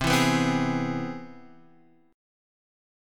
C+M7 chord